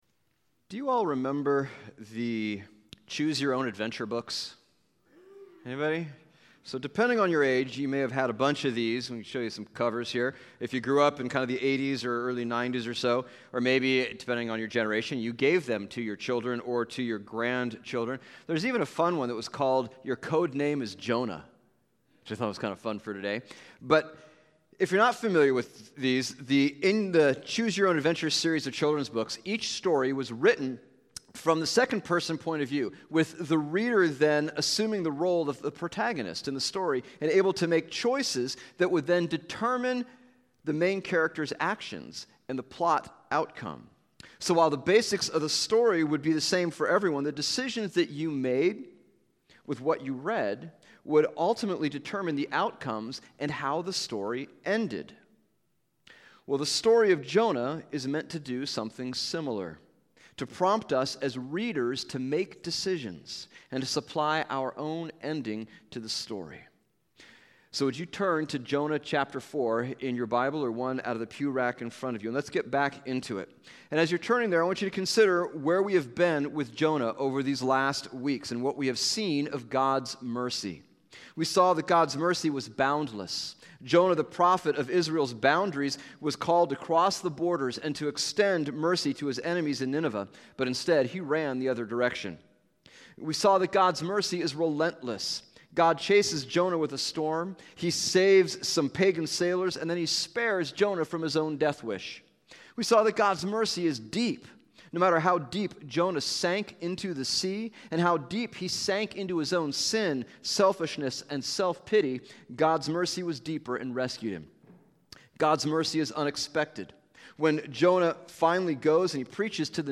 Jonah sermon series finishes with a focus on holding justice & mercy together.